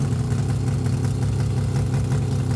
rr3-assets/files/.depot/audio/Vehicles/wr_02/wr_03_idle.wav
wr_03_idle.wav